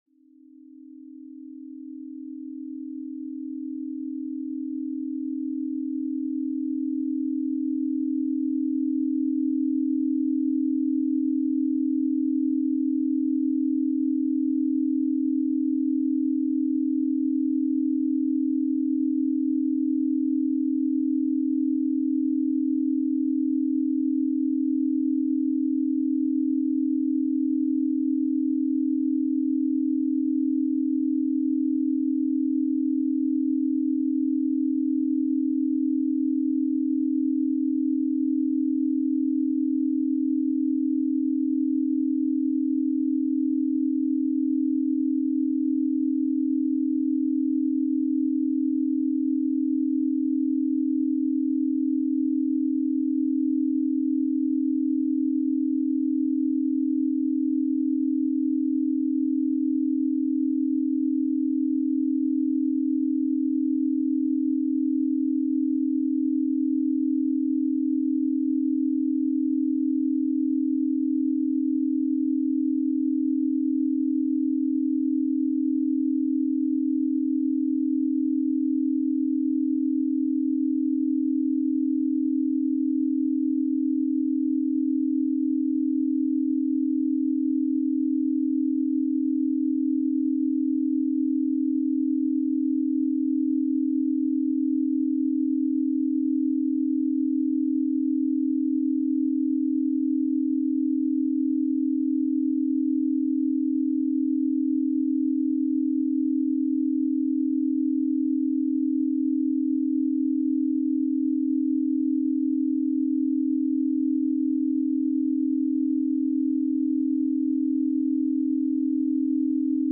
Searching for the perfect background sound to elevate your concentration and motivation?
Background Sounds, Programming Soundscapes, Coding Beats